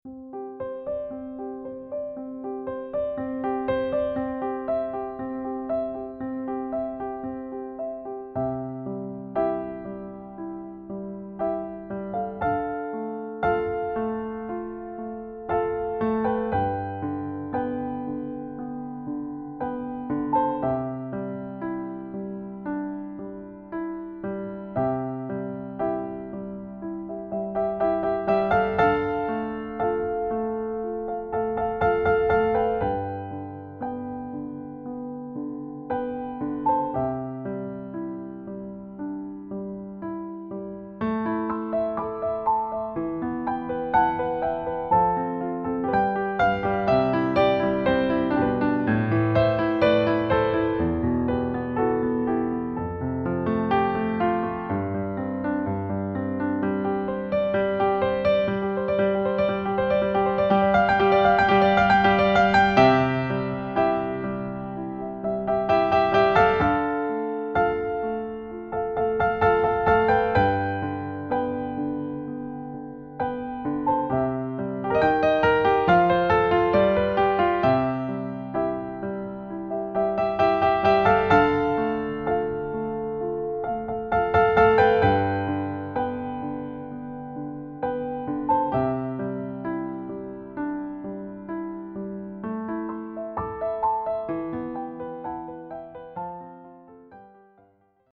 Ihre Trau(m)-Songs wunderschön am Piano gespielt
Hier einige Hörproben des Hochzeits-Pianisten (mp3):